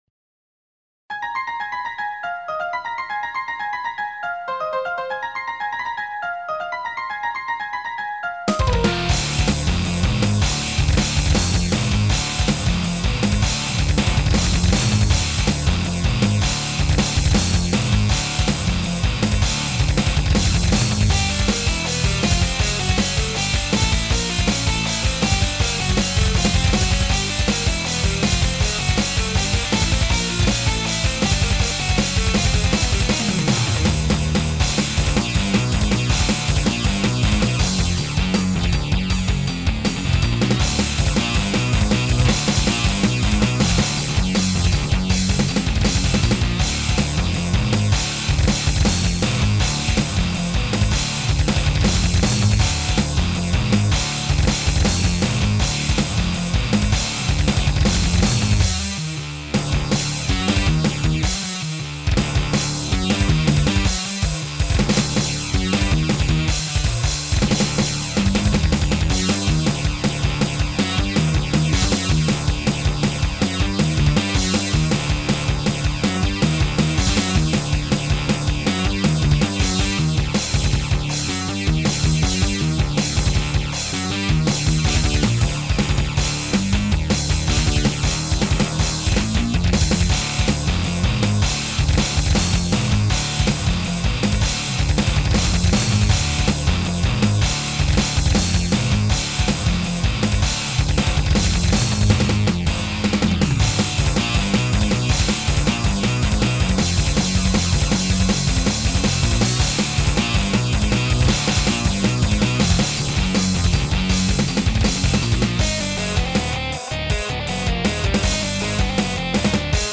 Наконец-то более-менее приемлемый звук гитар в экспорте. Классный эффект килл-свитча в риффе.
слишком затянутое интро, остальная компановка композиции вполне пропорциональна. не специалист по данному жанру-стилю, но полагаю соответствует
Интересная вещь, очень удачные смены темпа.
Какие-то какофоничные ноты просачиваются, возможно задумка Отличный вариант музыкальной темы к киберпанку, возможно даже постапокалиптическому
Впринципе неплохой конечно матмет, но вымученно звучит как-то
Гитара сверлит ухо норм. С 1:30 самый лучший момент, дисторшн не долбит по мозгам.